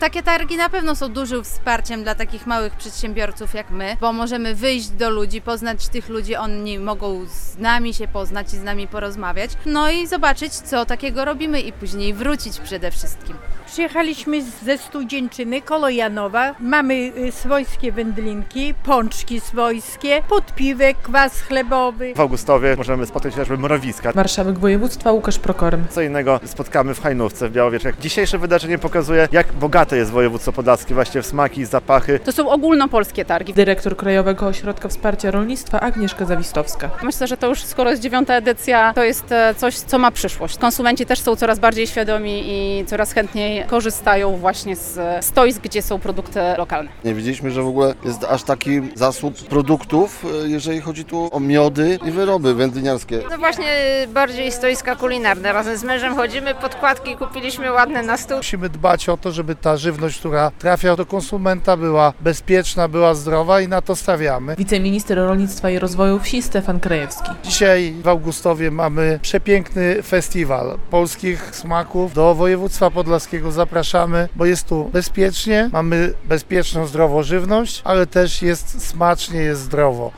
W Augustowie trwają targi "Polska smakuje".
Targi "Polska smakuje" - święto regionalnych produktów i tradycji - relacja
Marszałek województwa Łukasz Prokorym zwracał uwagę na różnorodność podlaskich potraw.
Wiceminister rolnictwa i rozwoju wsi Stefan Krajewski zaznaczał, że lokalna żywność jest przede wszystkim zdrowa.